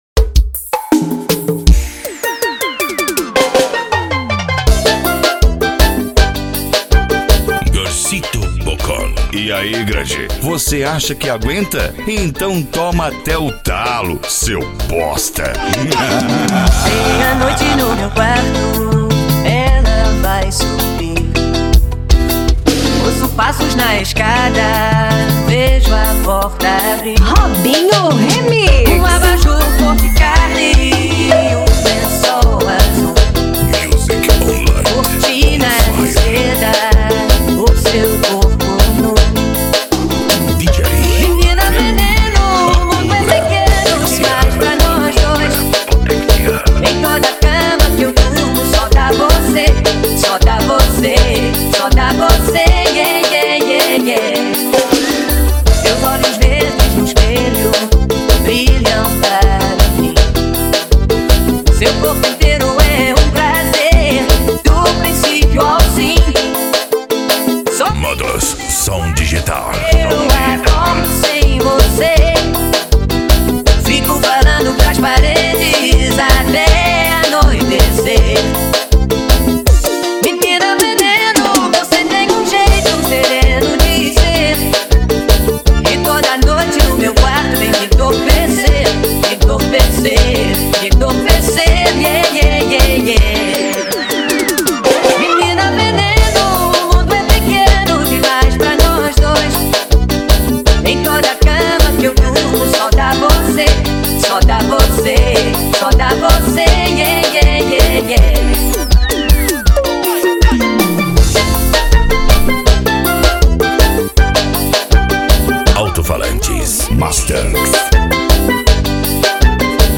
Bass
PANCADÃO
Psy Trance
Remix